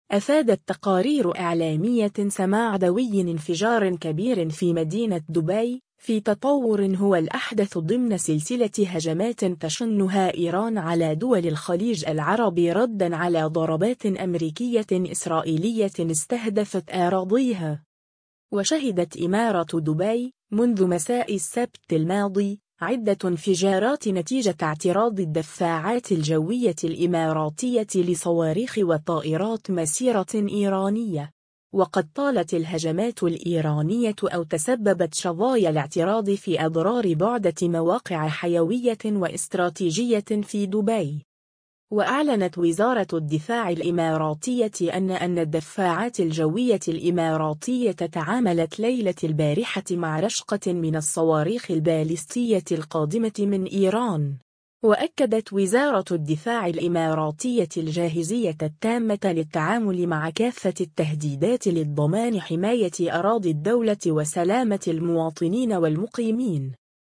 عاجل: دوي انفجارات عنيفة تهز دبي